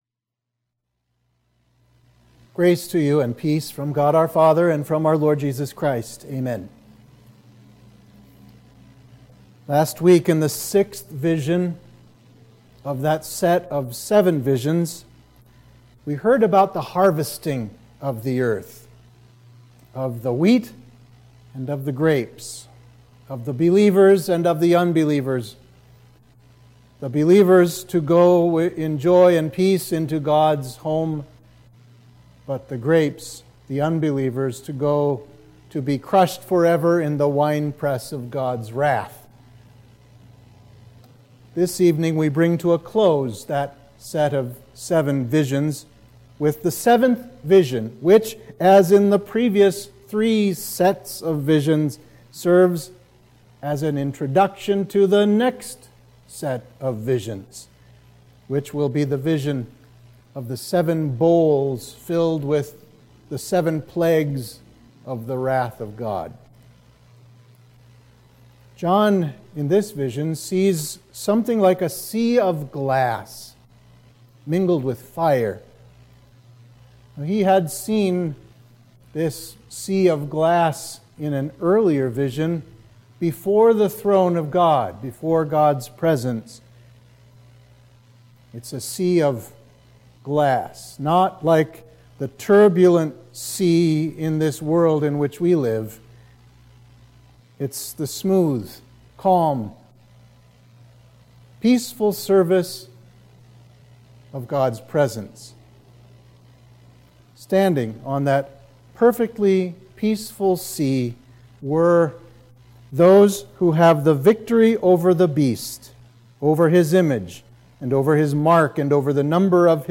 Midweek of Trinity 10